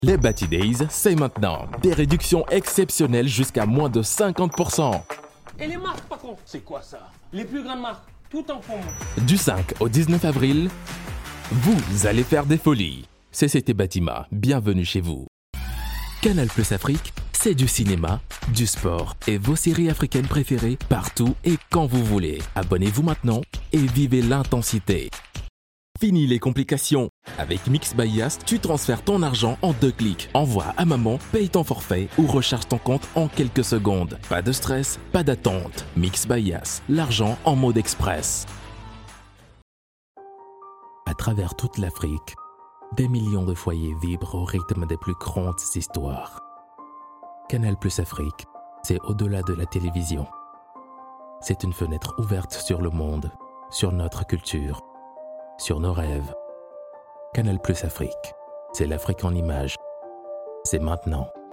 Voix off
Voix Off Africaine
Je travail en tant que freelance dans mon studio pro. le son sort clairement et sans bruit. j'ai une expérience de 8 ans et plus dans le métier de la voix off. j'ai travaillé pour plusieurs grande marque d'Afrique et du Monde.
voix off française d'afrique